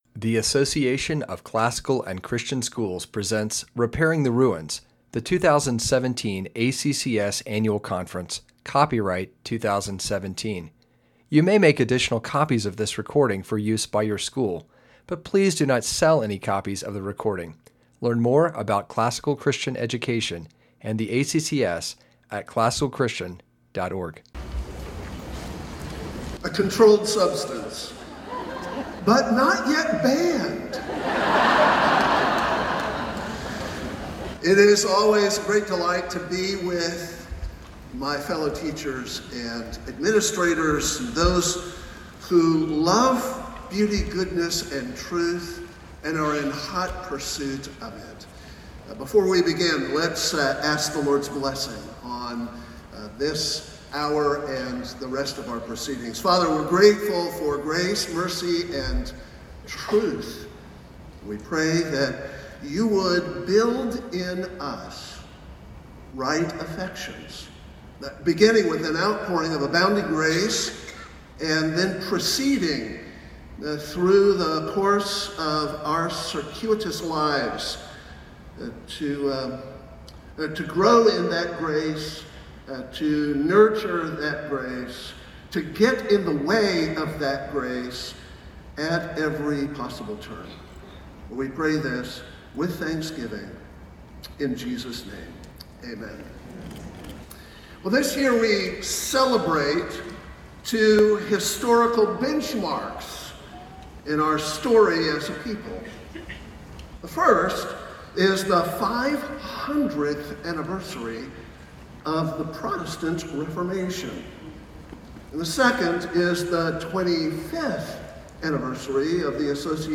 2017 Foundations Talk | 0:44:42 | All Grade Levels
Jan 9, 2019 | All Grade Levels, Conference Talks, Library, Media_Audio, Plenary Talk | 0 comments